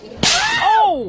Sound Effects
Slap Ahh Loud